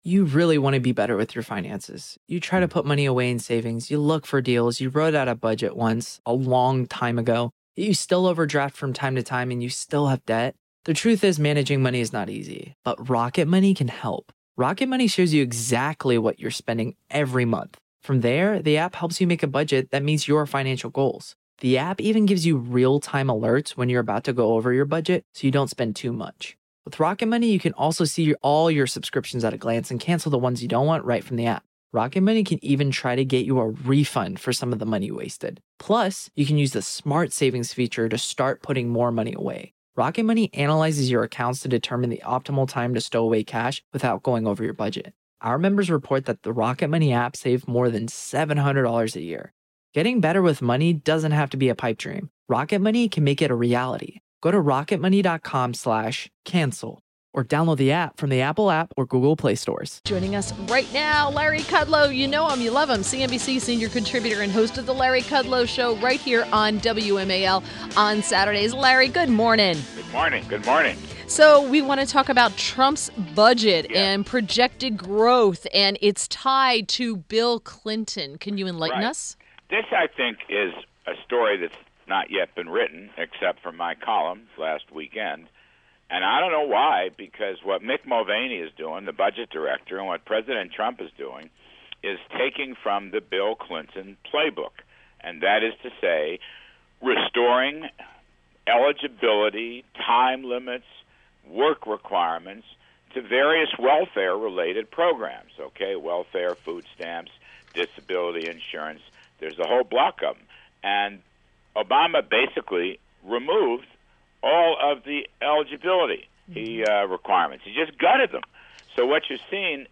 WMAL Interview - LARRY KUDLOW 05.30.17